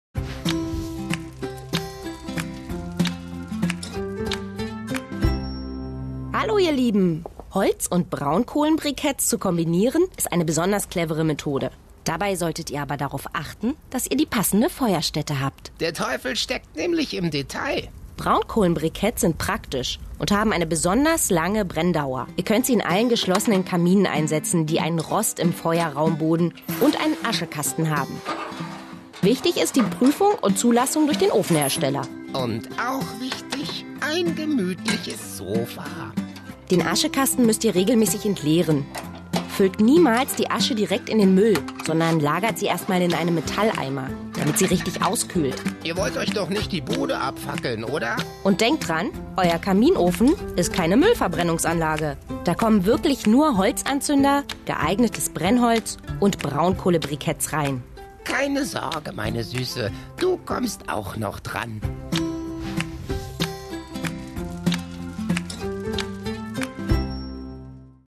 Meine Stimme ist frisch und jung. Seriös und warm. Und manchmal lasziv.
Sprechprobe: Werbung (Muttersprache):
My voice is fresh and young. Serious and warm. And sometimes lascivious.
Voice Over Videospot Bricketthersteller neu.MP3